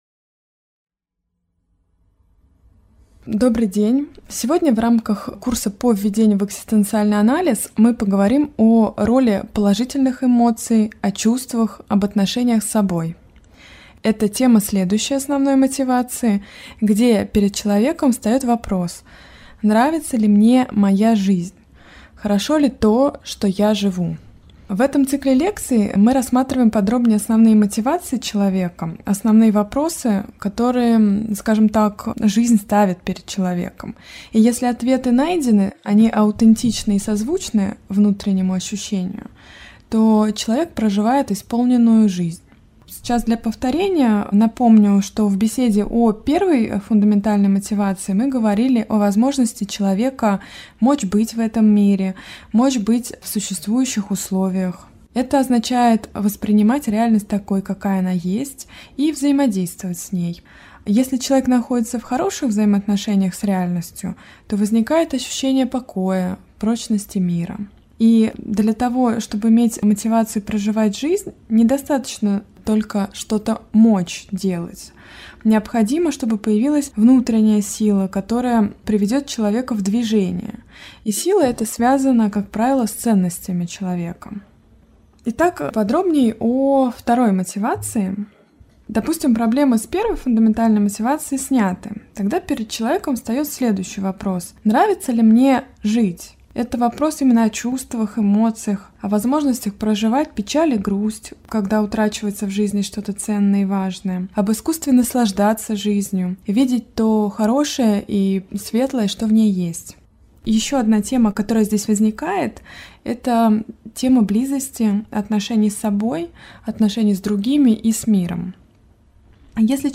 Аудиокнига Дружба с собой | Библиотека аудиокниг
Прослушать и бесплатно скачать фрагмент аудиокниги